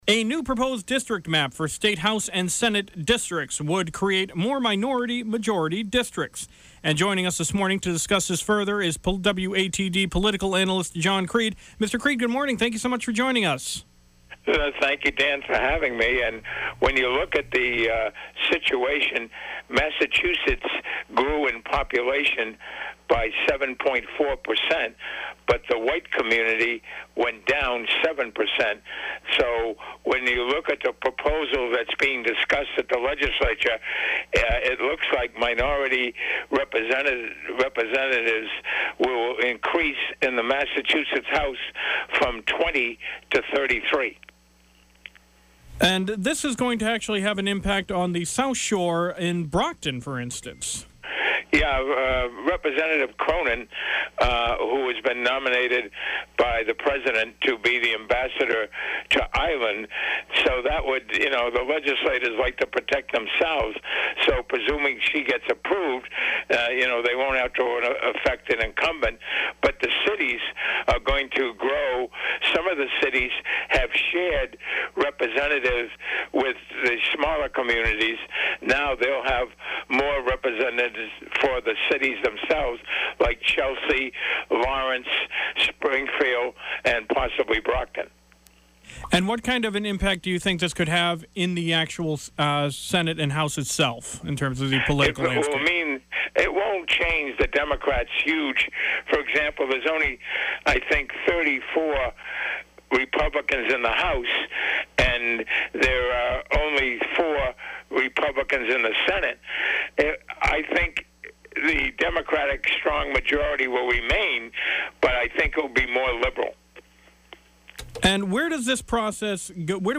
Political Analyst Discusses Proposed District Map